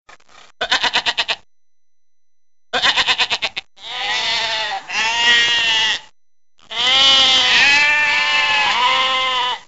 Sheep Ringtone
sheep.mp3